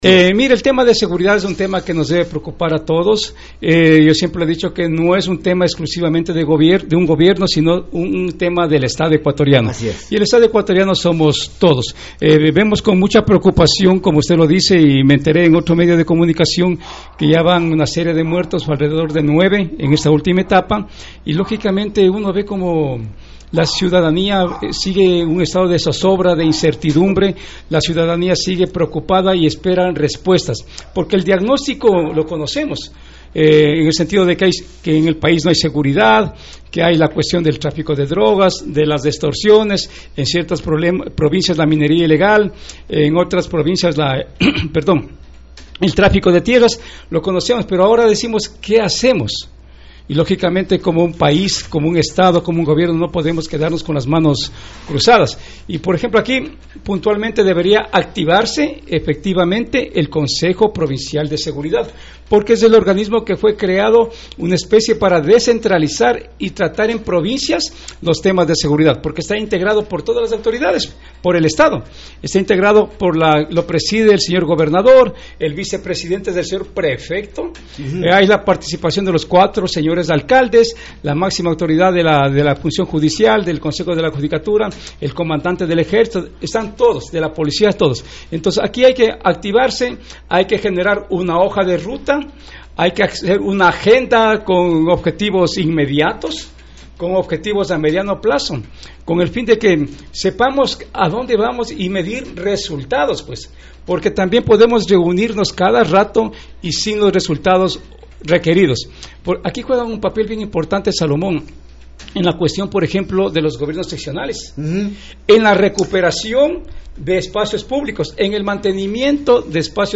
Entrevista: Ing. Jorge Paguay, exgobernador de Pastaza.